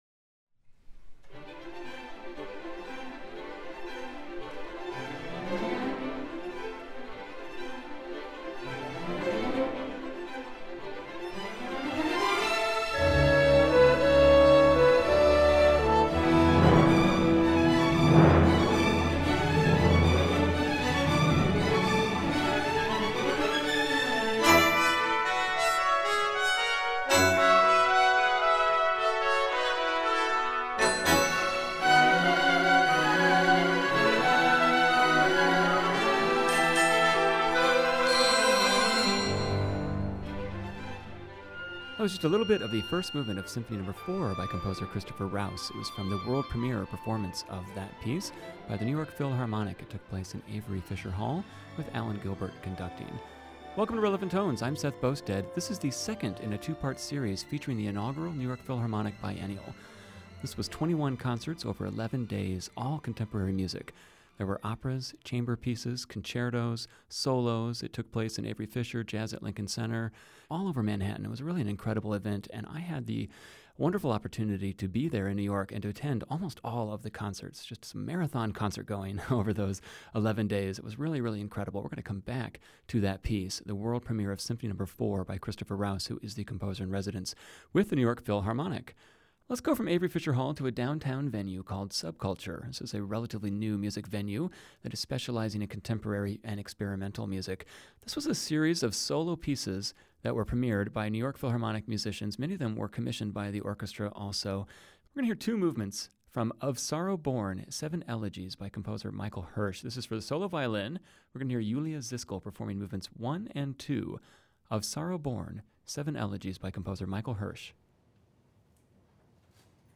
More music and interviews from the front lines of the New York Philharmonic’s inaugural Biennial, including the world premiere of Christopher Rouse’s Symphony No. 4.